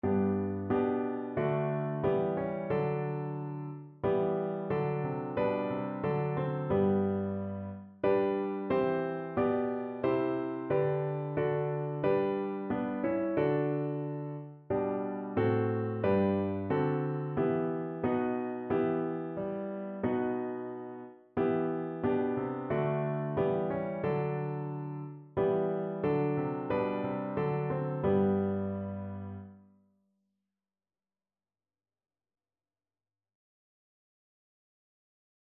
Notensatz 1 (4 Stimmen gemischt)
• gemischter Chor [MP3] 556 KB Download